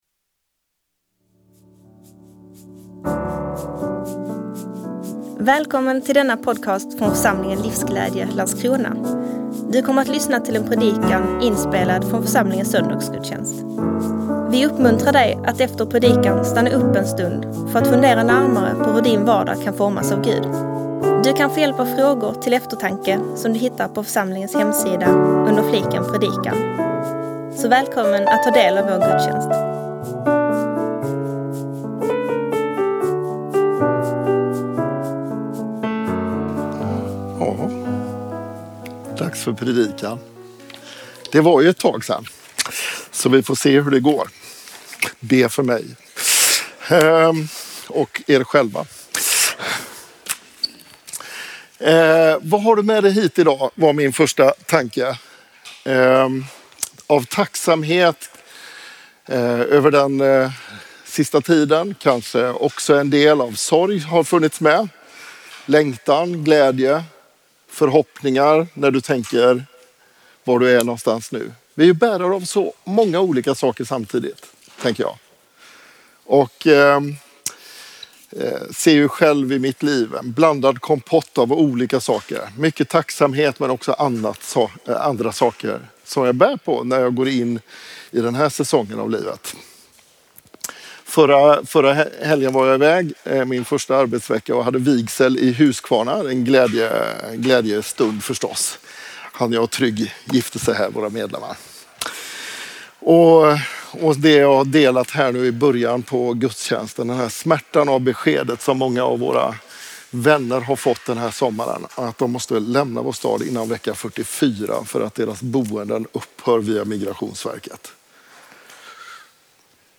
Predikant